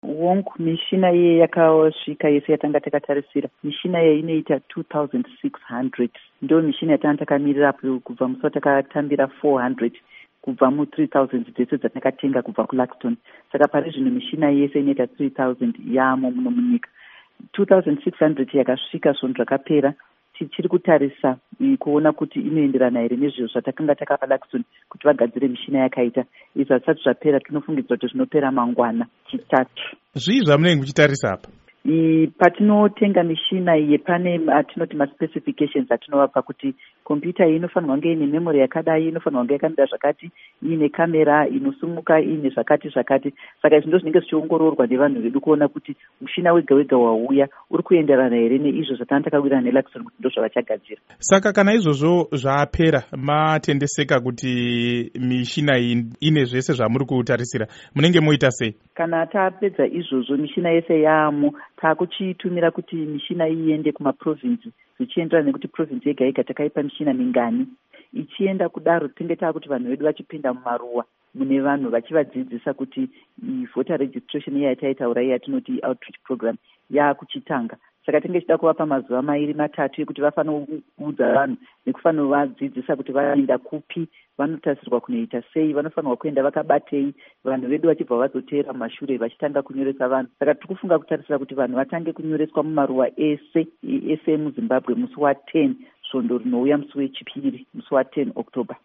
Hurukuro naAmai Rita Makarau